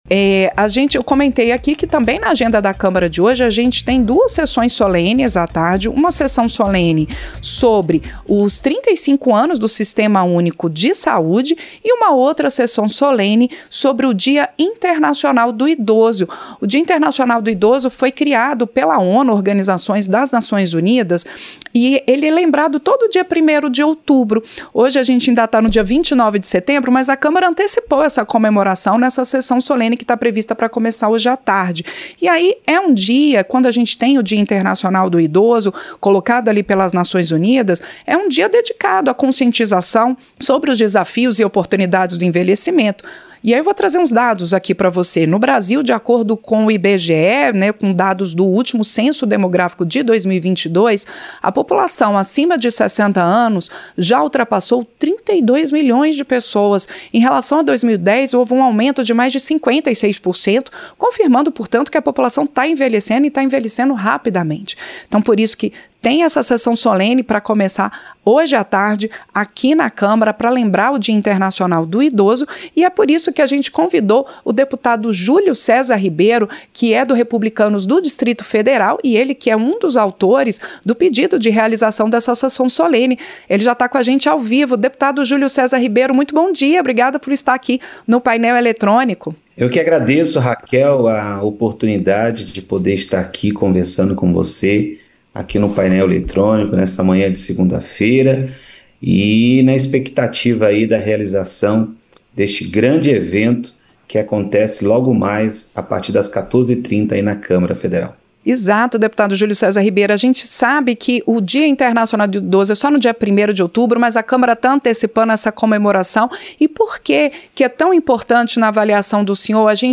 • Entrevista - Dep. Julio Cesar Ribeiro (Rep-DF)
Programa ao vivo com reportagens, entrevistas sobre temas relacionados à Câmara dos Deputados, e o que vai ser destaque durante a semana.